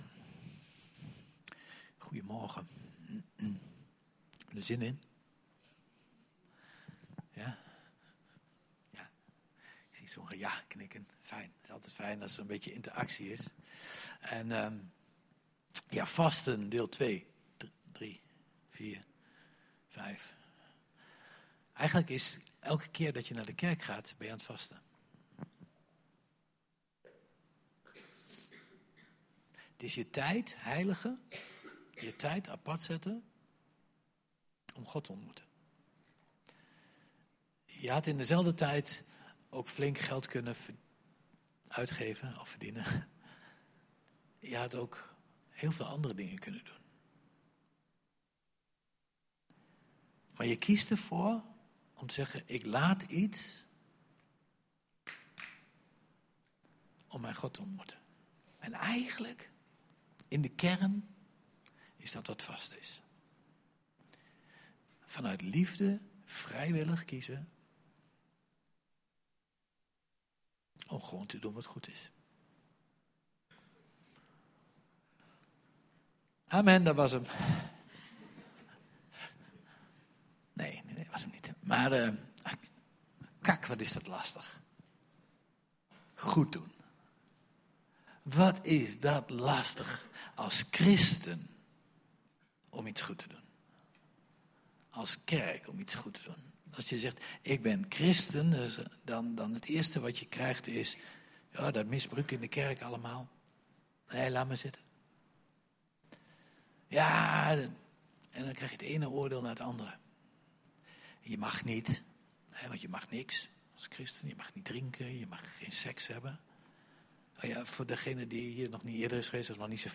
Door vasten laten we iets om iets anders terug te ontvangen. Hoe dit gaat is te luisteren in de preek!